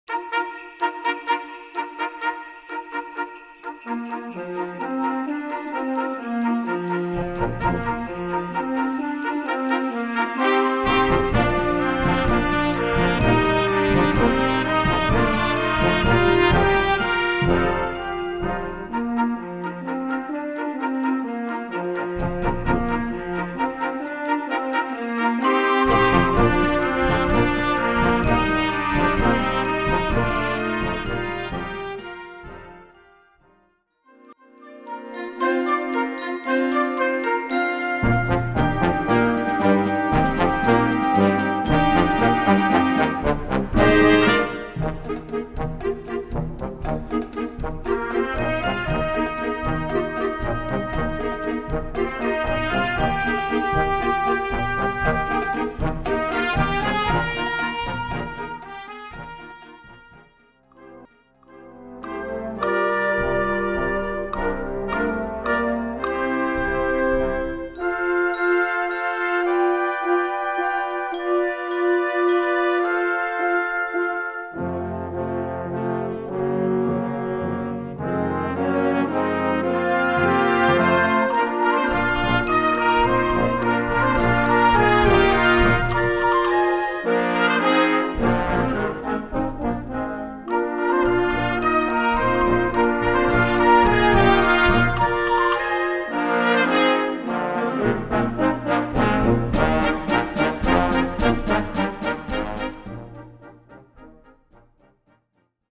Répertoire pour Harmonie/fanfare - Educatif